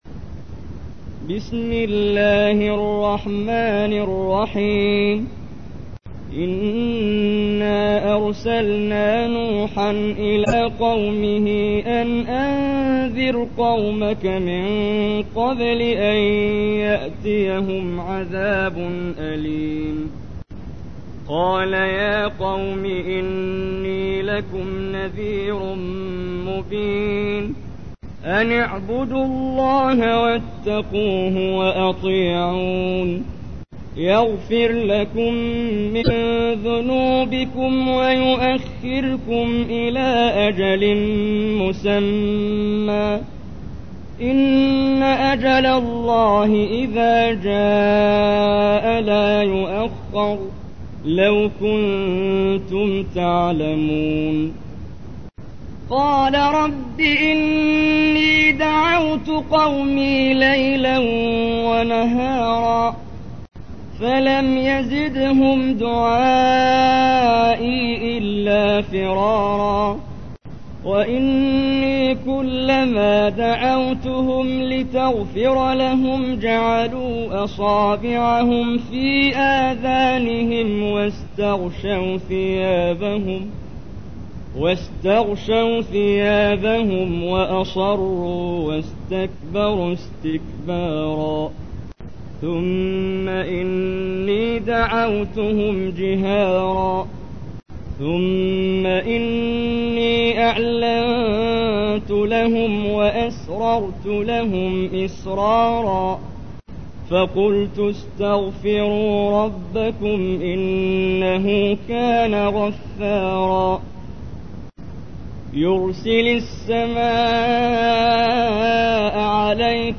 تحميل : 71. سورة نوح / القارئ محمد جبريل / القرآن الكريم / موقع يا حسين